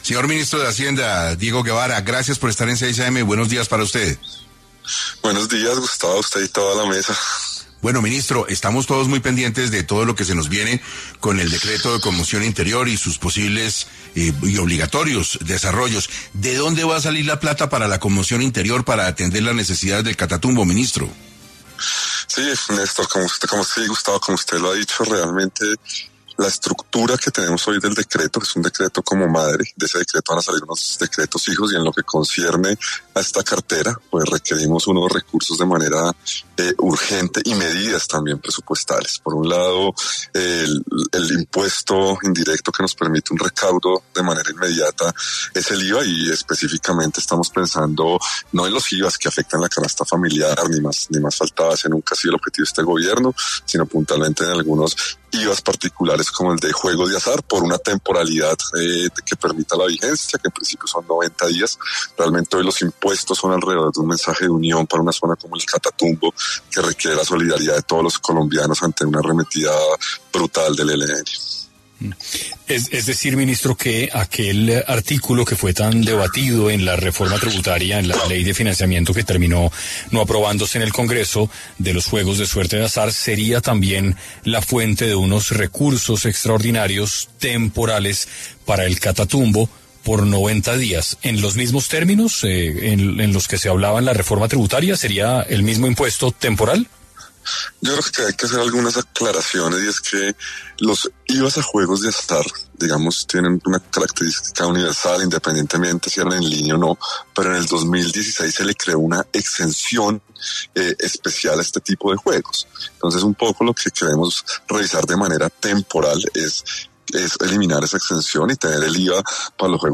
En 6AM de Caracol Radio estuvo Diego Guevara, Ministro de Hacienda, para hablar cuál es la propuesta del Gobierno para el recaudo de los recursos de la conmoción interior en el Catatumbo.